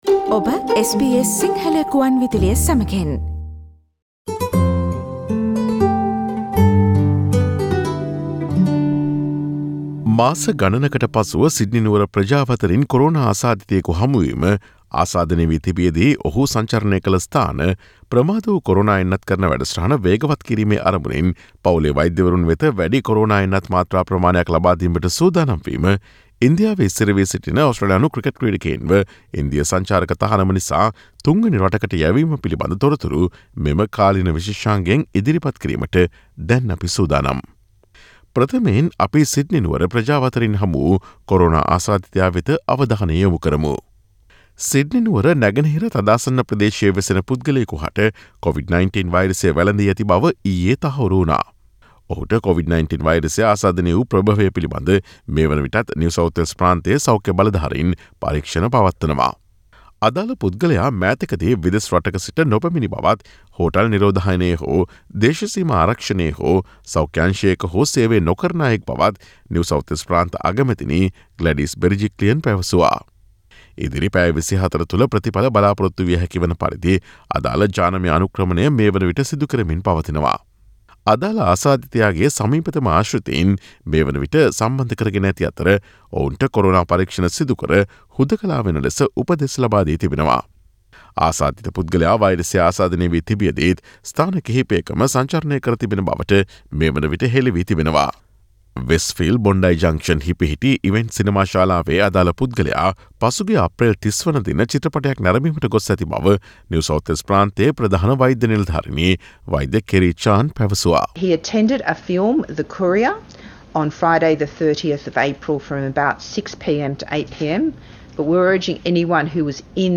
SBS Sinhala feature on Sydney man's COVID-19 infection and Australia's COVID-19 vaccination program.